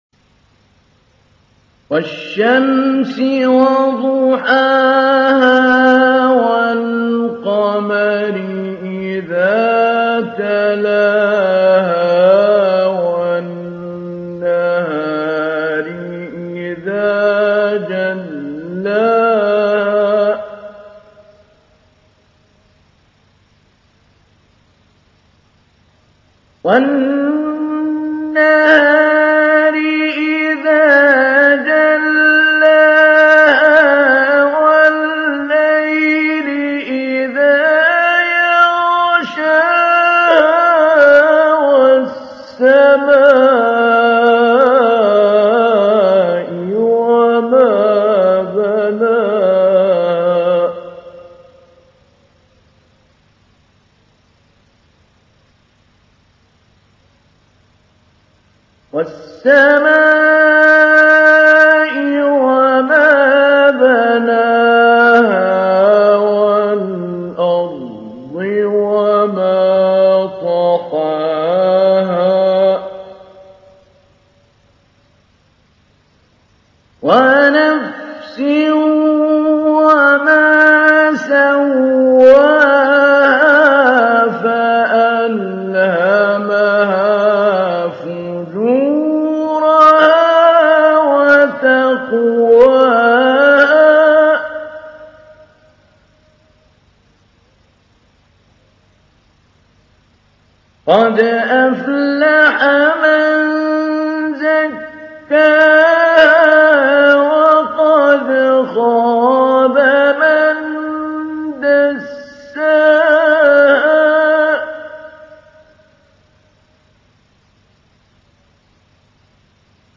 تحميل : 91. سورة الشمس / القارئ محمود علي البنا / القرآن الكريم / موقع يا حسين